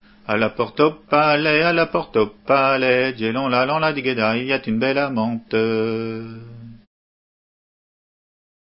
Entendu au festival des "Assemblées gallèses" en juillet 89